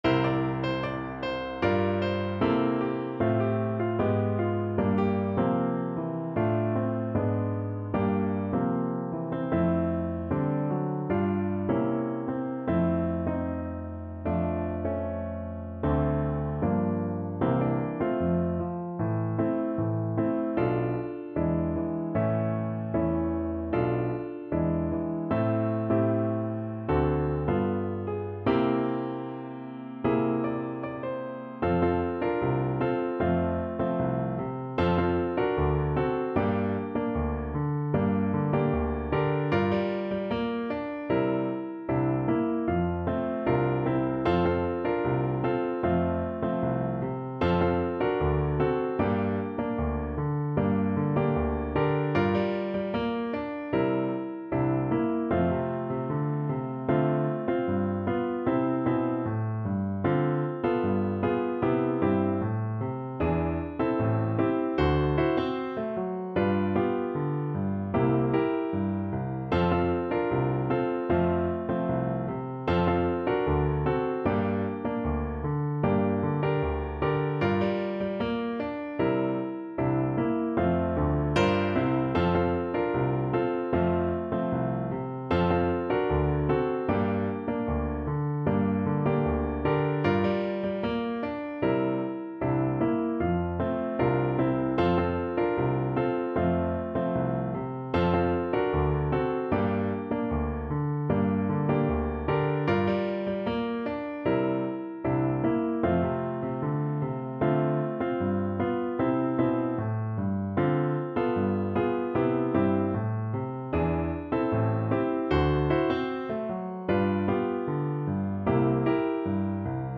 2/2 (View more 2/2 Music)
Pop (View more Pop French Horn Music)